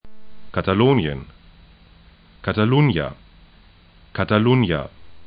Katalonien kata'lo:nĭən Catalunya / Cataluña kata'lʊnja es/ ca Gebiet / region 41°40'N, 01°30'E